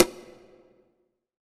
CONGA 15.wav